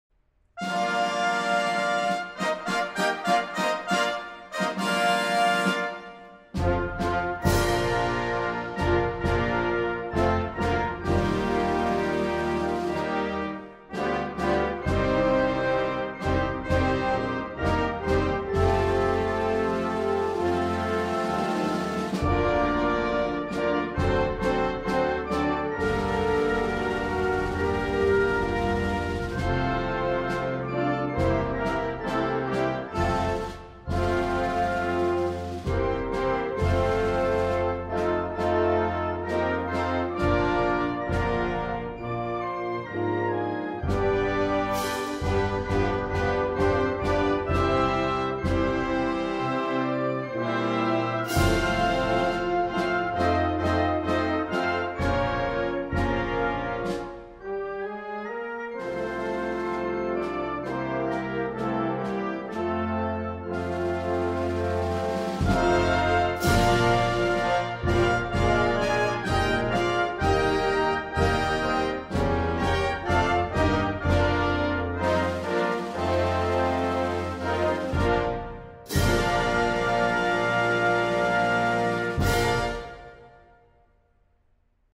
Музыка без слов, душа Грузии